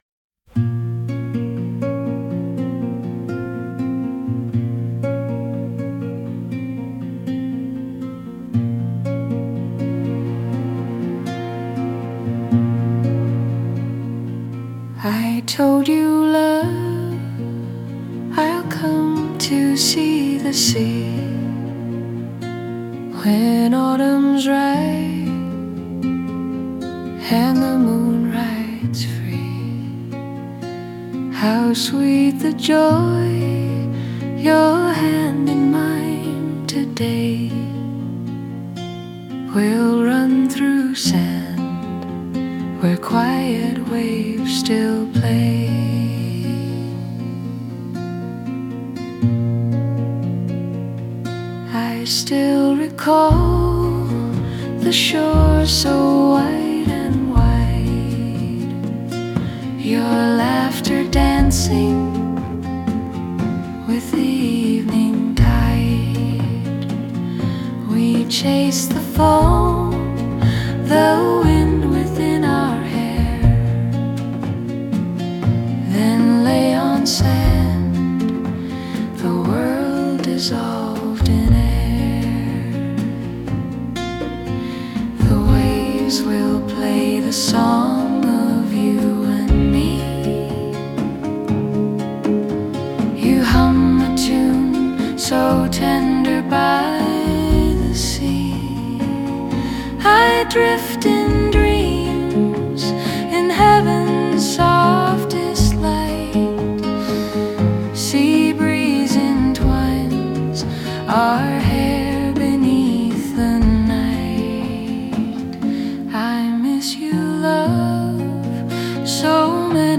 musical lyric version, keeping it natural and singable in English — something that could fit a slow waltz, bossa nova, or soft acoustic ballad